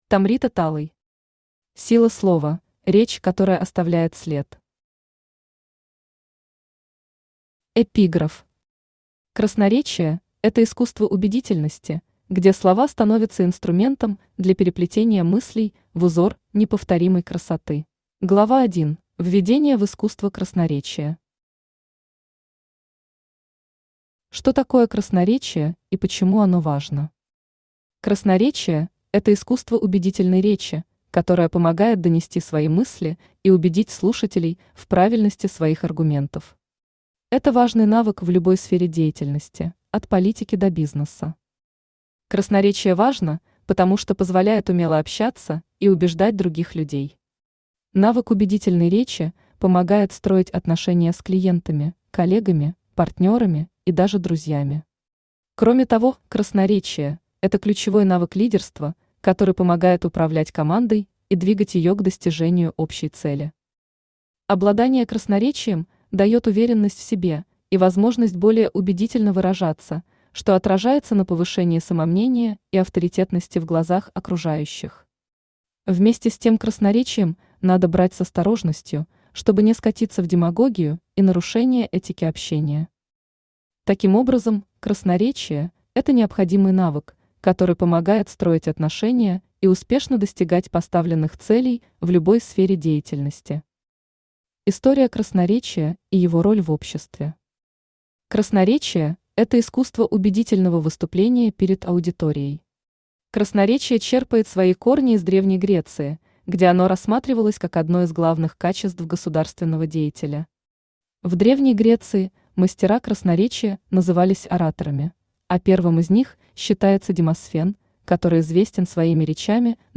Аудиокнига Сила слова: Речь, которая оставляет след | Библиотека аудиокниг
Aудиокнига Сила слова: Речь, которая оставляет след Автор Tomrita Talay Читает аудиокнигу Авточтец ЛитРес.